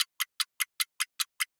TickTock 03.wav